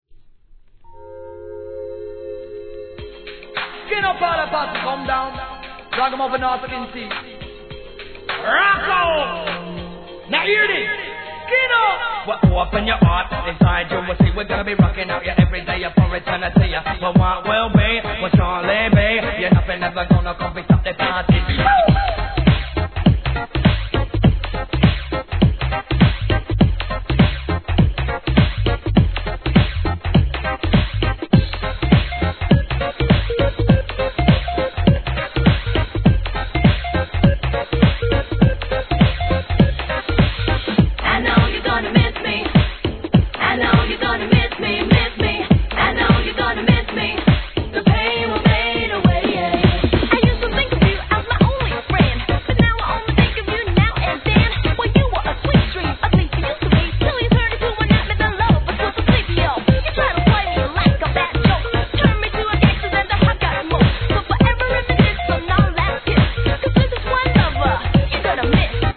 HIP HOP/R&B
POPなダンスナンバーにラガHIP HOP仕立てで怒キャッチーな一曲!